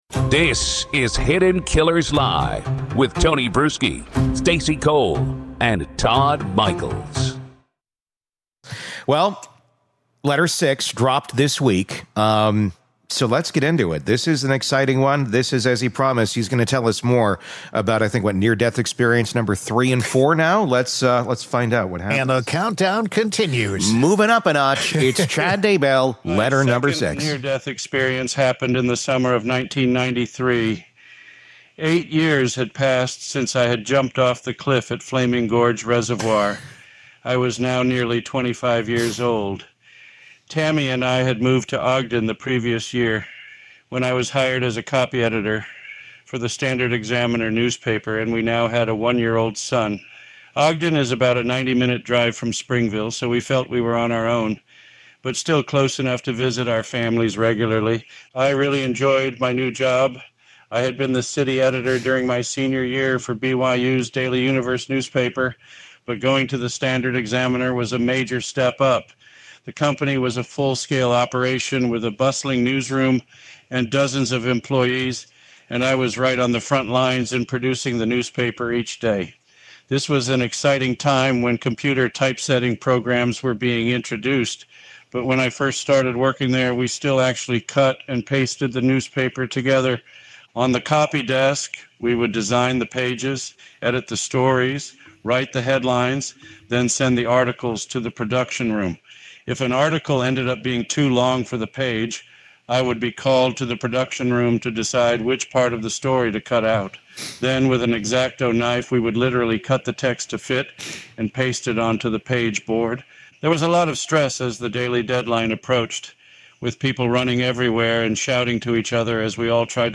The hosts rip into the absurdity while also highlighting the darker undertones: Daybell genuinely believed he was chosen, guided by spirits, and immune to accountability.